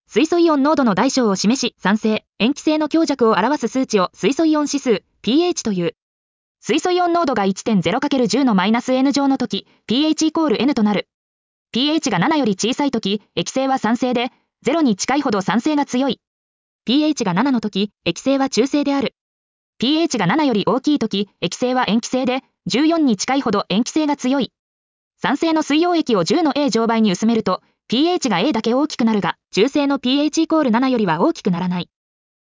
• 耳たこ音読では音声ファイルを再生して要点を音読します。通学時間などのスキマ学習に最適です。
ナレーション 音読さん